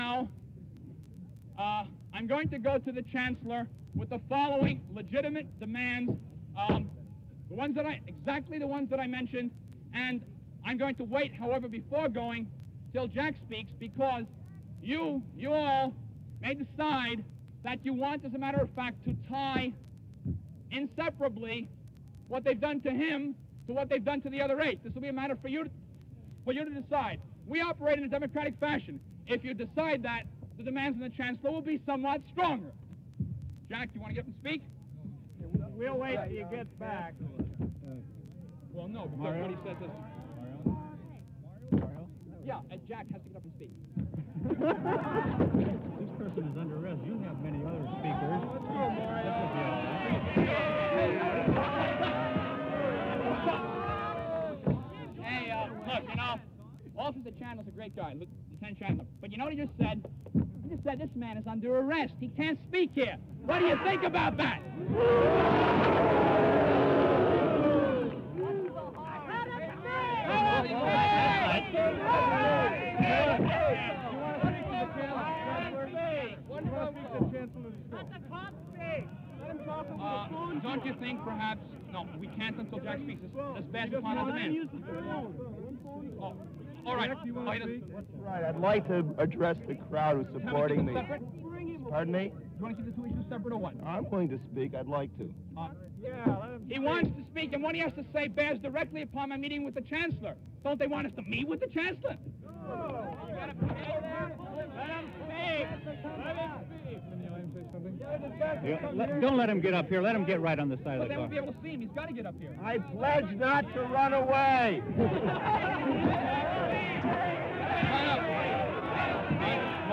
October 1, 1964, Sproul Plaza, UC Berkeley